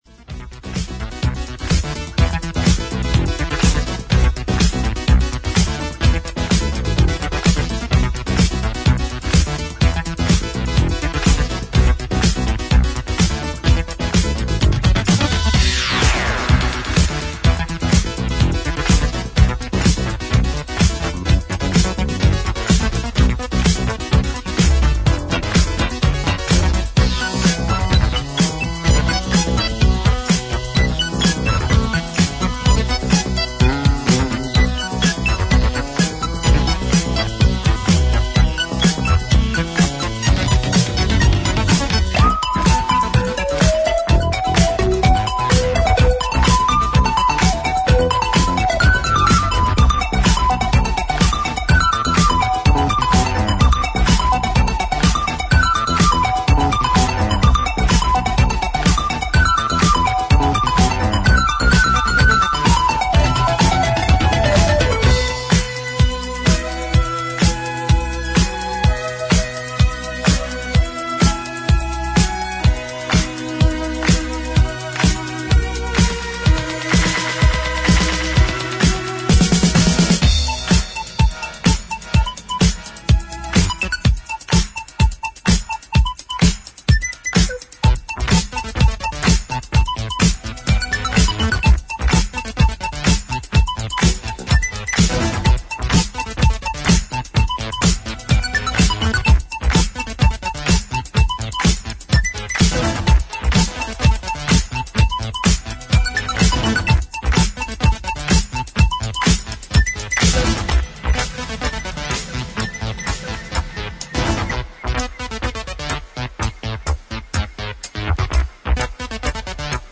キャッチーでファニーな電子音のメロディが印象的な、イタロ・ライクのディスコ・チューン